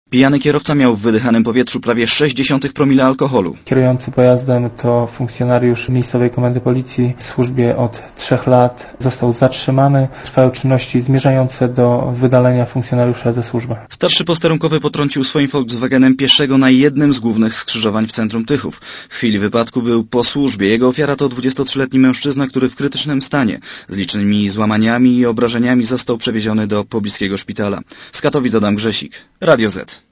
Korespondencja reportera Radia Zet